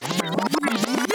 tape-rewind2.wav